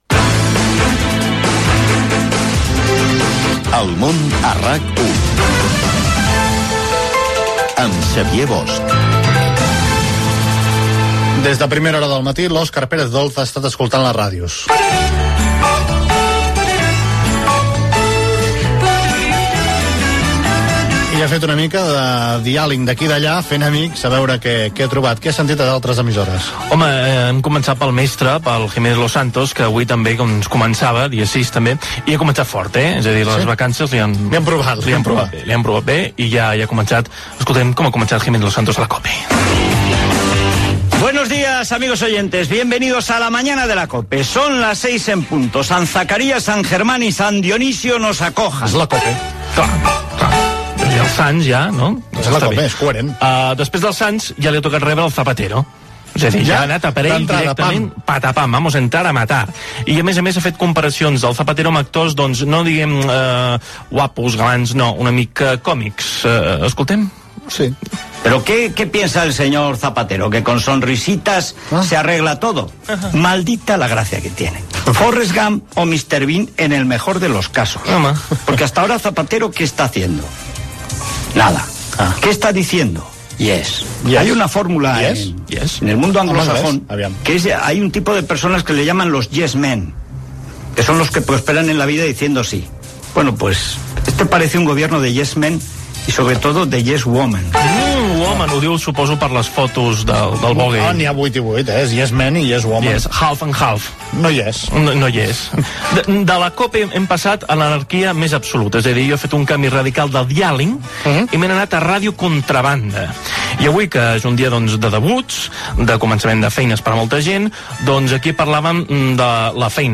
Primera edició del programa. Indicatiu del programa, secció "El dialing" amb un recull del que emeten altres emissores (COPE, Contrabanda FM, Radio 2, RM Radio,), invitació a la participació i indicatiu del programa
Info-entreteniment